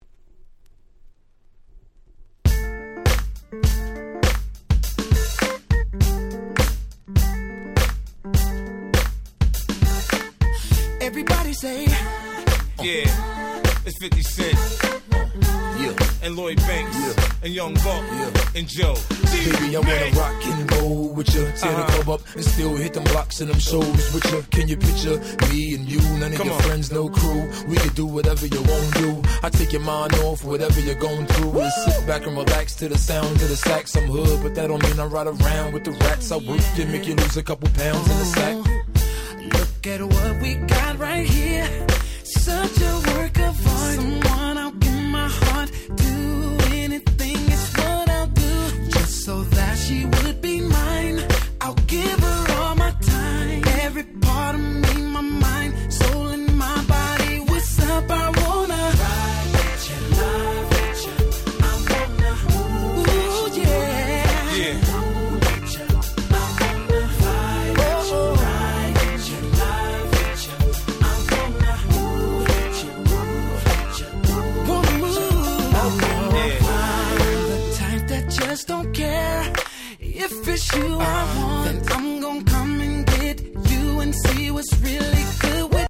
04' Big Hit R&B !!